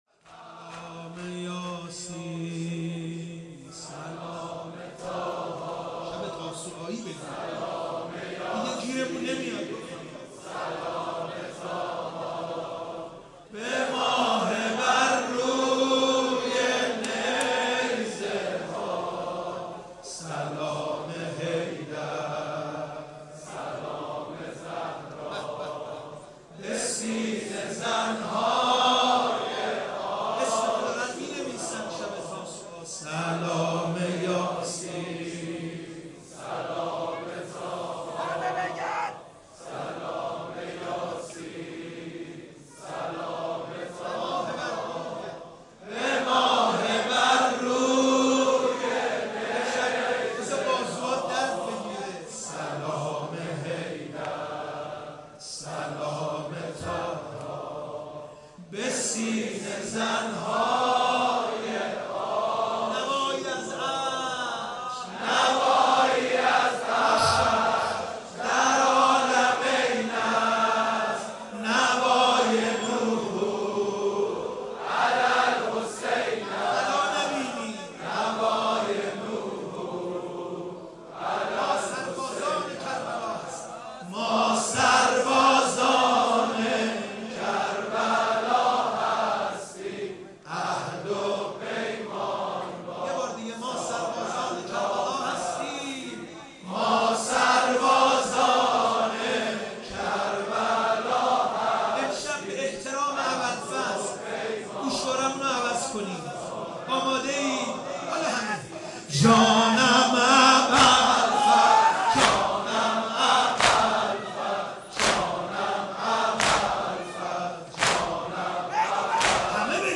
شب نهم تاسوعا محرم
نوحه جديد
مداحی صوتی